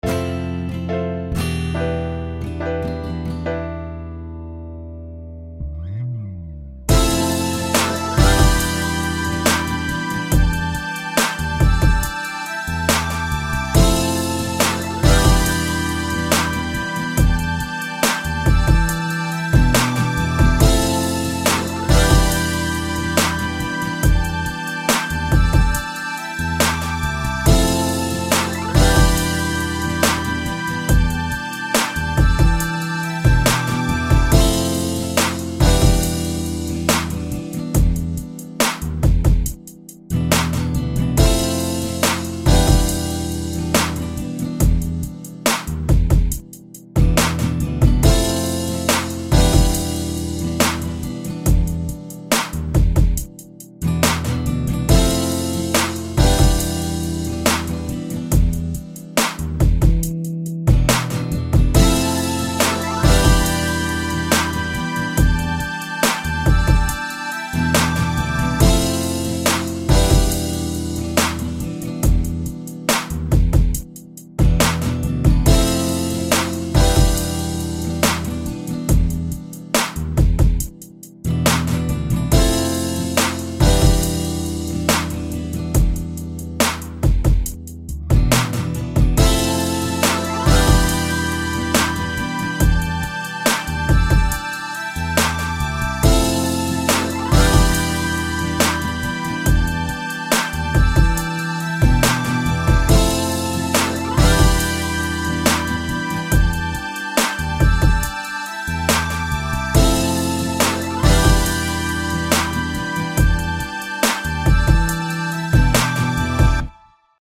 그냥 심심해서 옛날 샘플링 스타일로 만들어봤숨뉘당.....
코드 두개 무한반복이네여 힣헤히헤허ㅏㅣ;ㅁㄴ앎ㄴㅇㄹ
사비에서 midi느낌이 좀 나네요 ㅋ